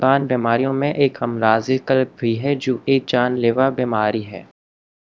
deepfake_detection_dataset_urdu / Spoofed_TTS /Speaker_03 /11.wav